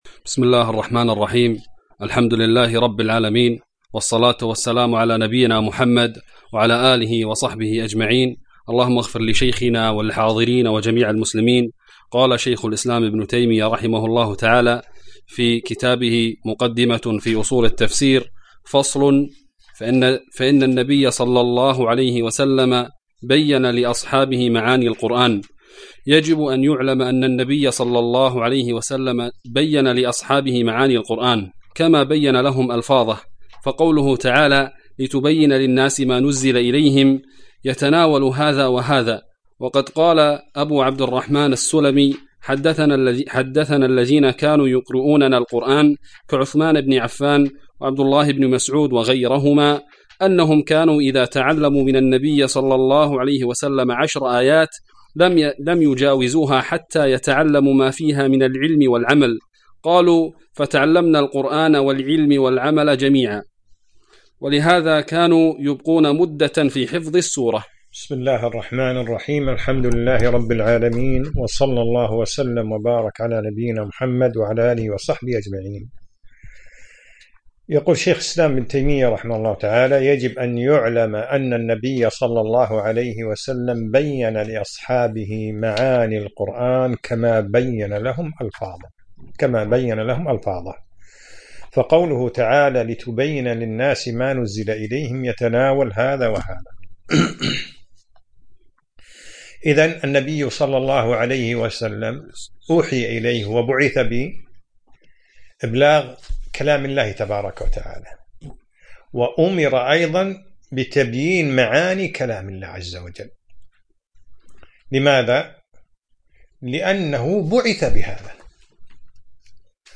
الدرس الثاني : فصل في عناية الصحابة والتابعين بمعاني القرآن